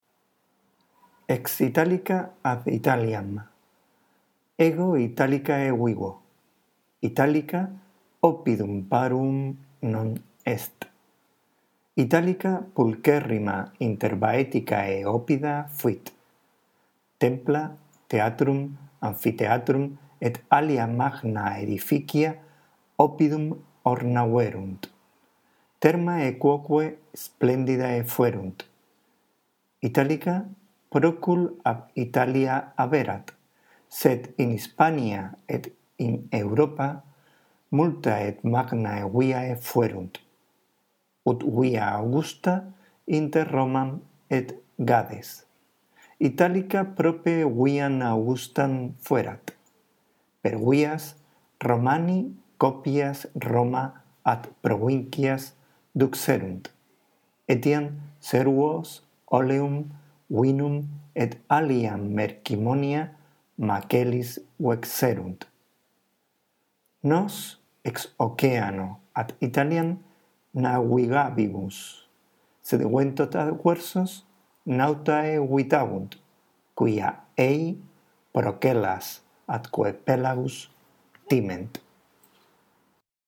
Lee despacio y en voz alta el texto en latín y no te olvides de respetar los signos de puntuación; presta atención también a las notas que acompañan al texto. La audición de este archivo te ayudará en la práctica de la lectura: